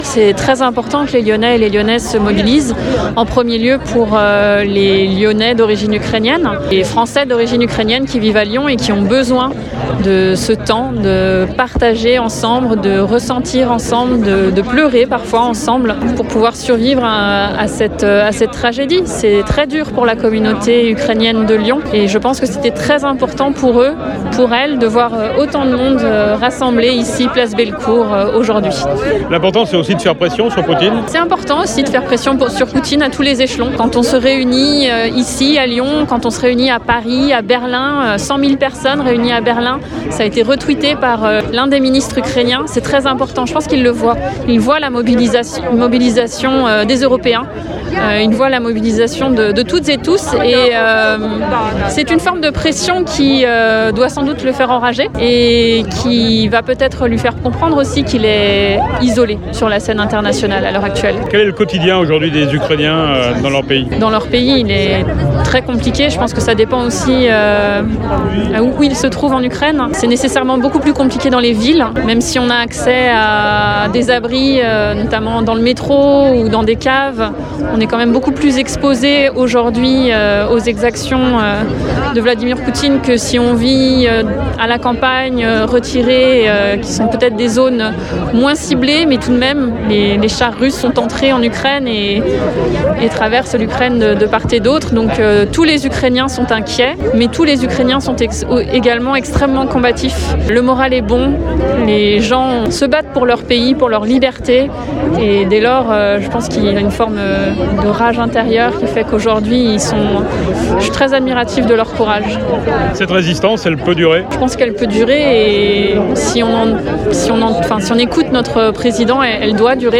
Sonia Zdorovtzoff, ajointe au maire de Lyon déléguée aux Relations et à la Coopération Internationale, elle-même d’origine ukrainienne, était présente lors du rassemblement de dimanche à Lyon.